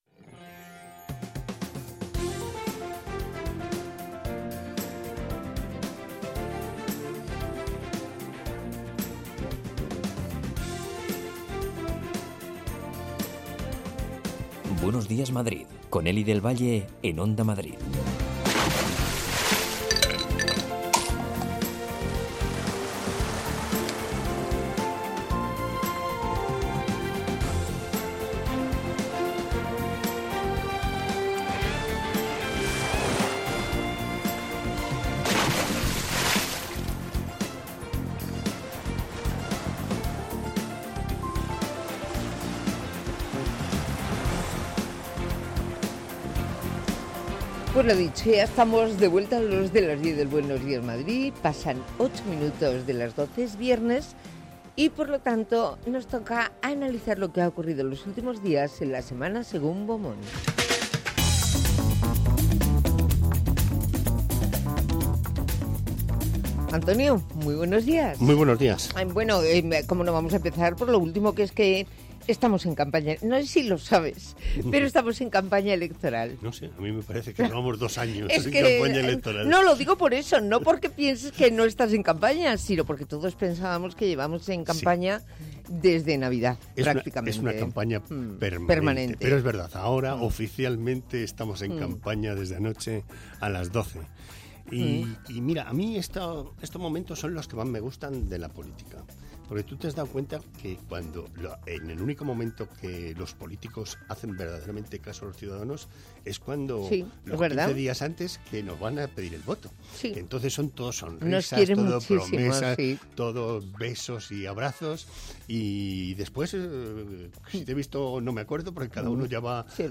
recorre en el Buenos Días Madrid con los oyentes todos los puntos de la comunidad. Tres horas más de radio donde se habla de psicología, ciencia, cultura, gastronomía, medio ambiente y consumo.